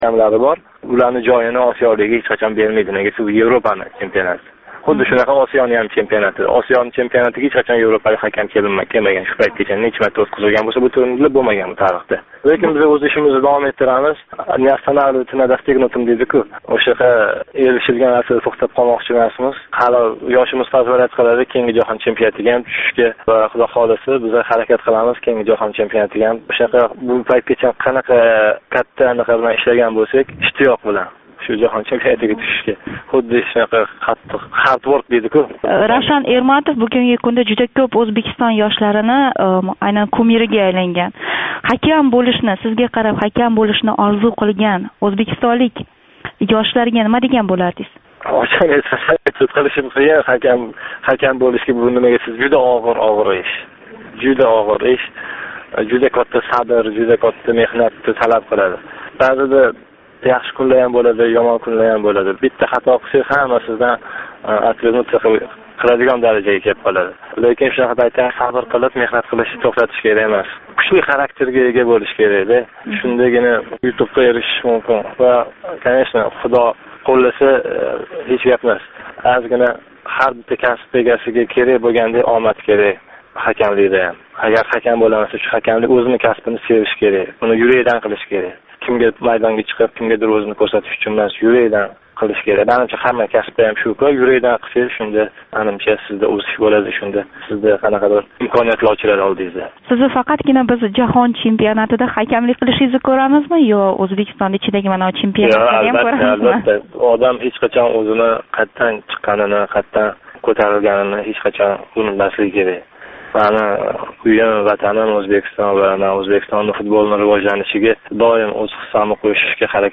"7 кун - Ўзбекистон": Ҳафта давомида Ўзбекистон сиëсий¸ иқтисодий-ижтимоий ҳаëти¸ қолаверса мамлакатдаги инсон ҳуқуқлари ва демократия вазияти билан боғлиқ долзарб воқеалардан бехабар қолган бўлсангиз "7 кун - Ўзбекистон" ҳафталик радиожурналимизни тинглаб боринг. Бу туркум ҳафтанинг энг муҳим воқеалари калейдоскопидир!